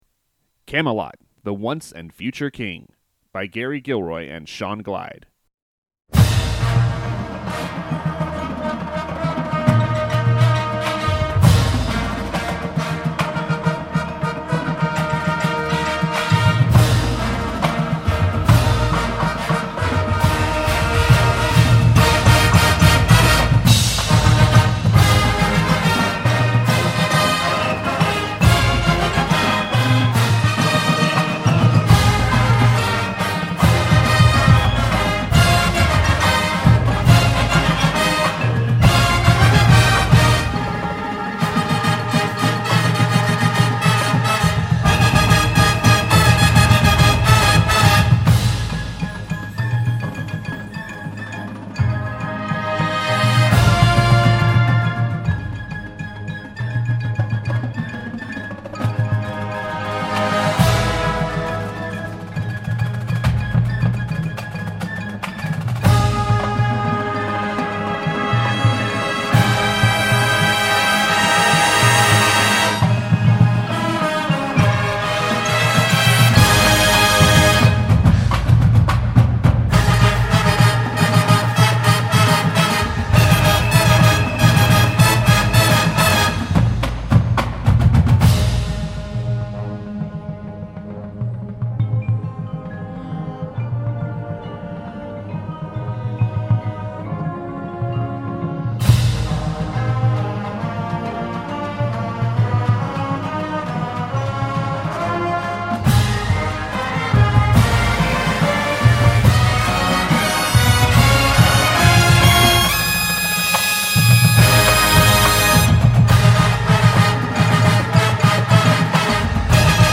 a medieval themed Marching Band Show scored for larger bands